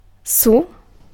Ääntäminen
IPA : /ɪn/ US : IPA : [ɪn]